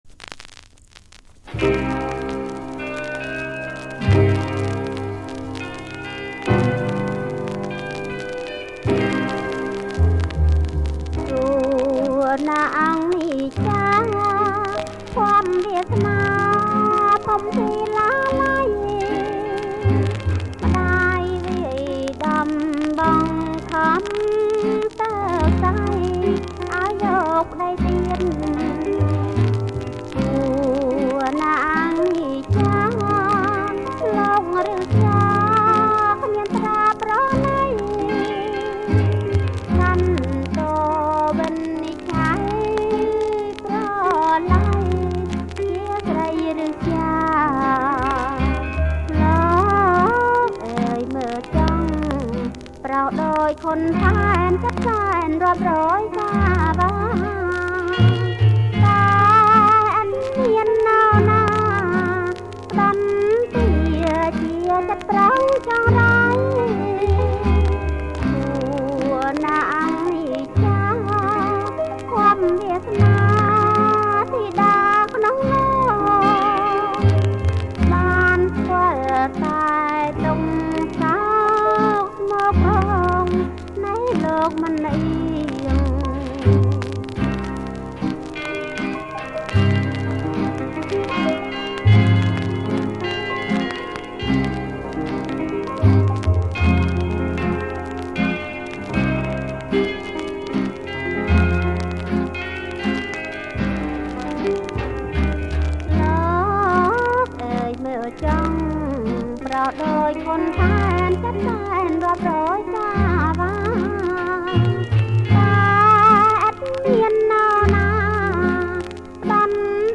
• ប្រគំជាចង្វាក់ Boston